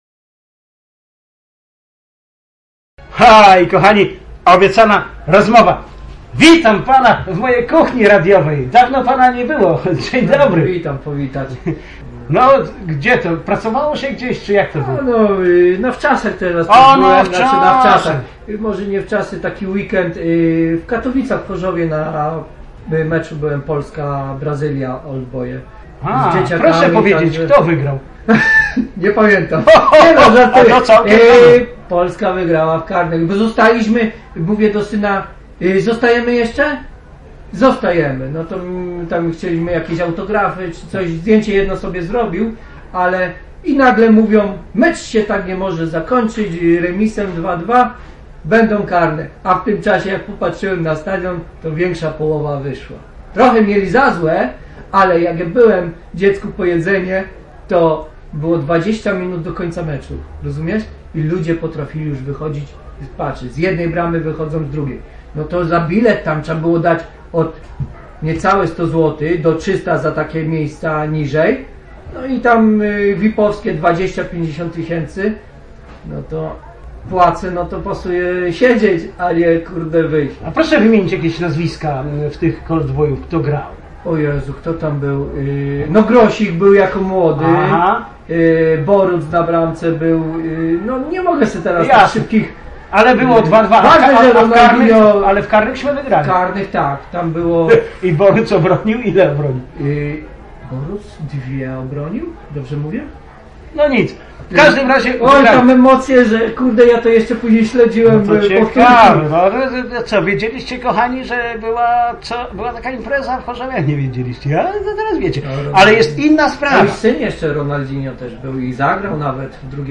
Grupa ponad stu osób z tzw. obywatelskich patroli przyjechała na granicę. Piątek 27 czerwca, godz. 19.30. Rozmawiam o tym na Bazarze przy Hali Targowej w Mielcu.
A30.06.Rozmowy-przy-straganie.Bojowki-na-zachodniej-granicy.mp3